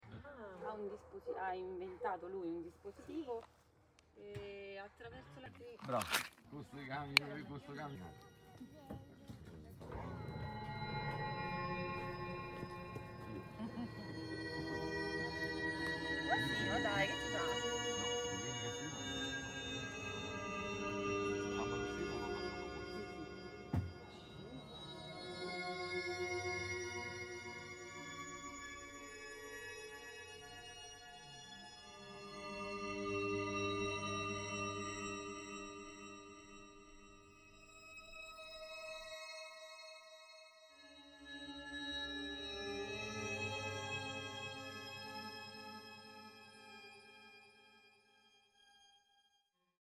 at Fantasie di Lavanda, Alatri (FR)